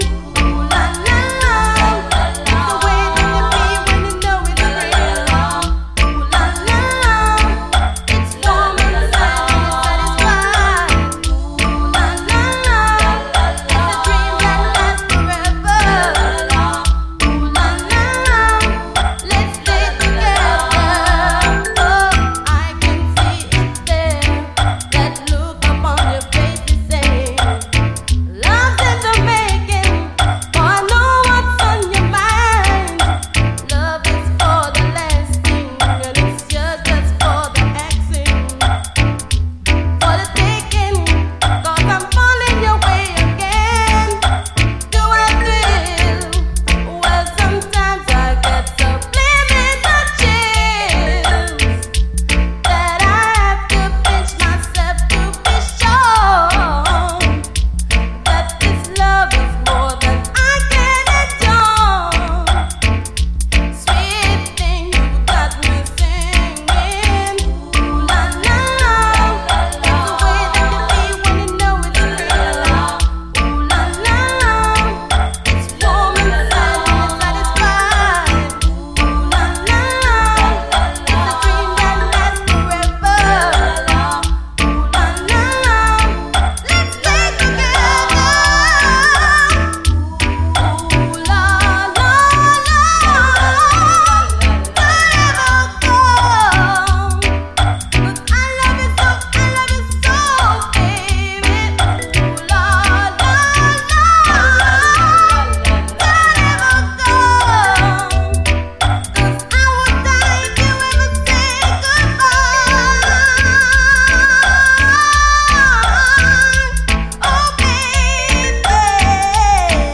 REGGAE
インスト・ダブ・ヴァージョンも収録！